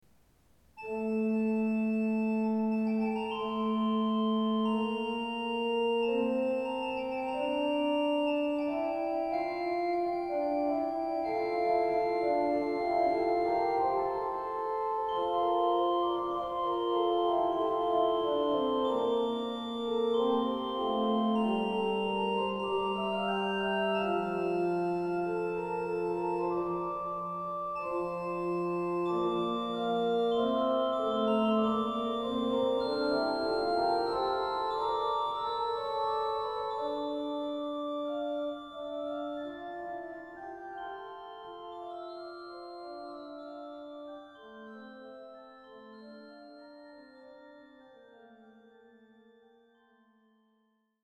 21. Orgelchoräle Es gibt enorm viele Choralbearbeitungen für die Orgel.
Deshalb werden Sie in der Folge einige Orgelchoräle anhören und die zugehörige Registrierung studieren können.